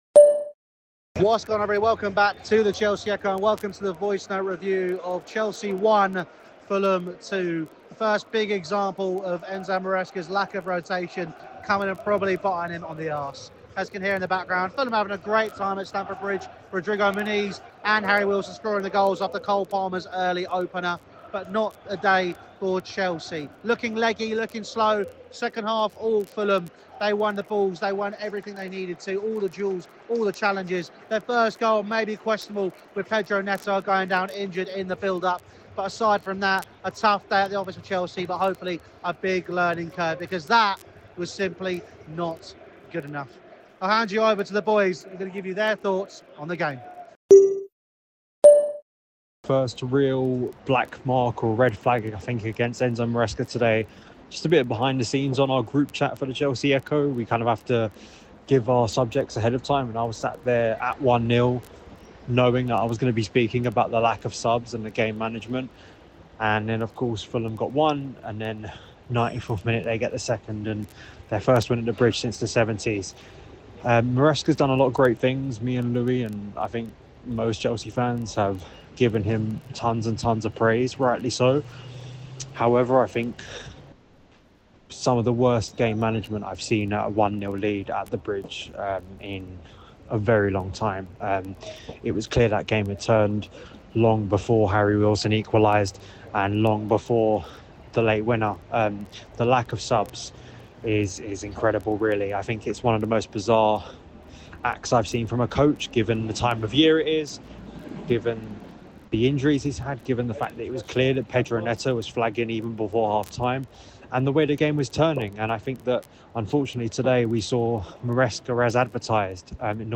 Boxing Day Hangover... | Chelsea 1-2 Fulham | Voicenote Review